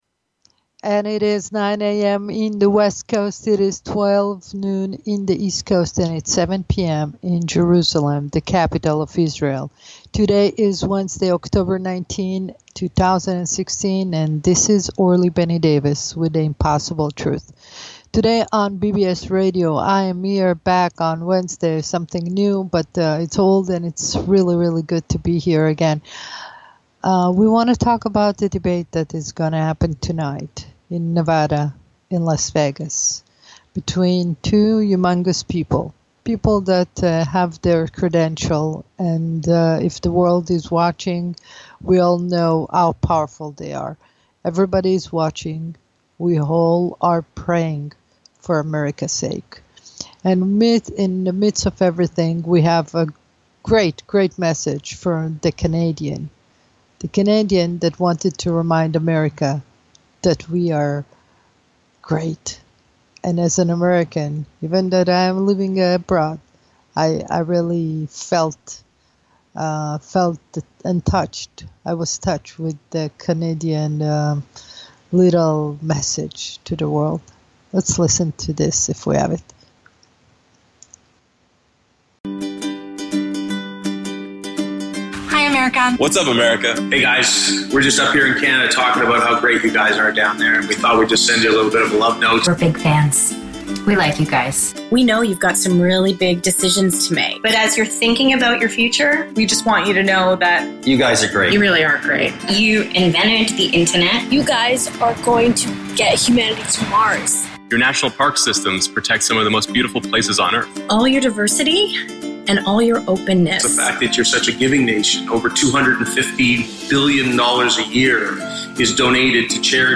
Subscribe Talk Show